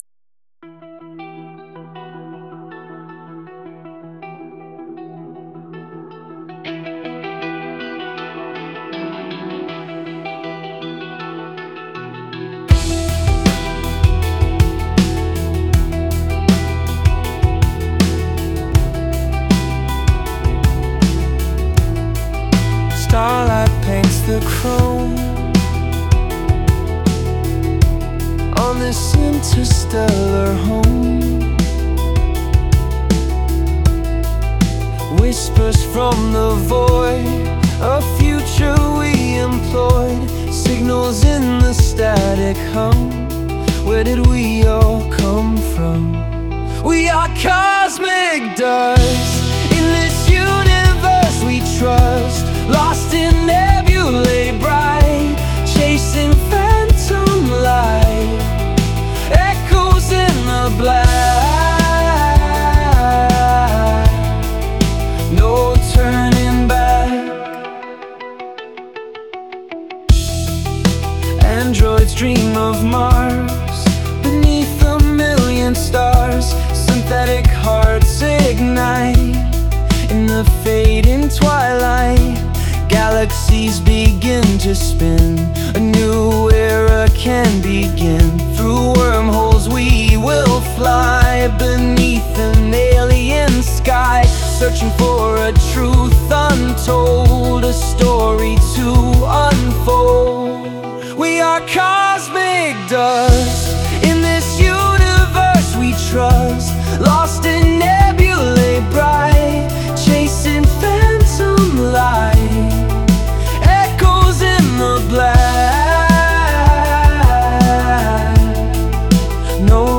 "Sci-fi indie rock"